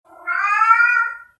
고양이5a
cat5a.mp3